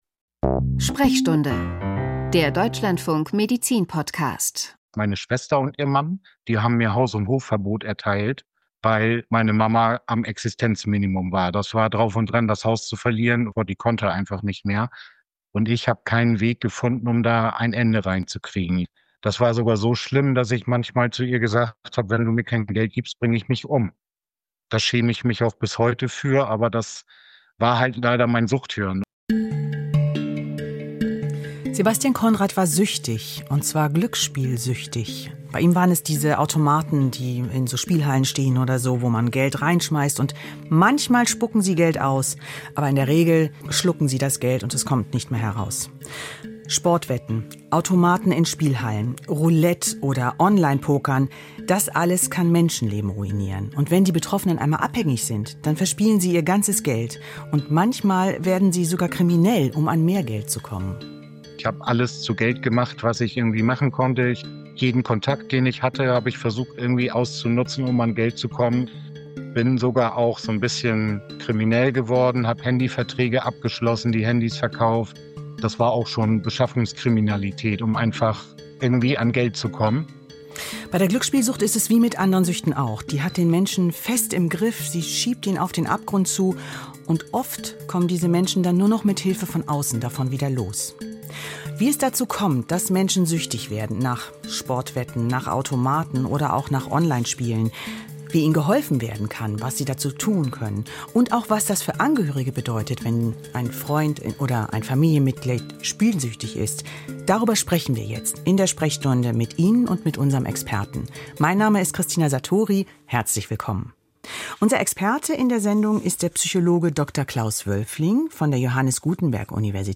Ein Experte gibt Auskunft über den Erkenntnisstand seines medizinischen Fachgebietes und beantwortet anschließend Hörerfragen; die Sendung wird ergänzt durch einen aktuellen Info-Block.